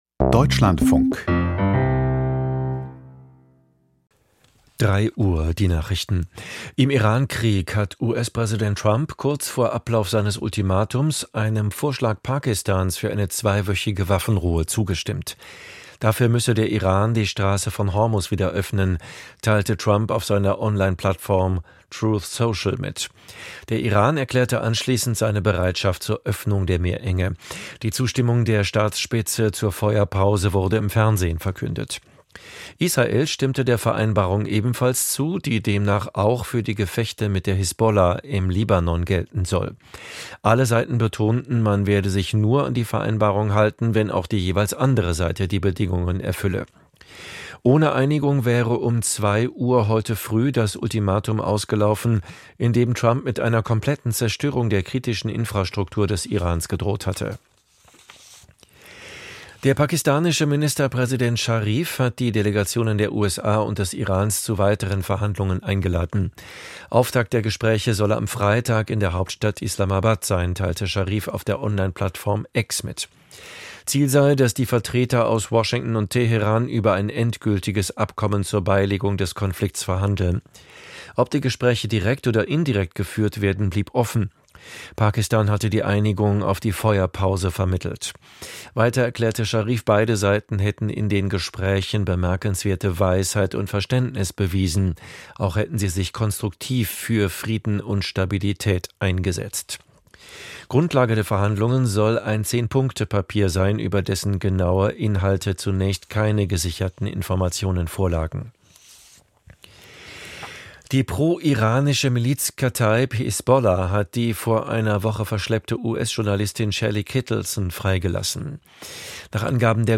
Die Nachrichten vom 08.04.2026, 03:00 Uhr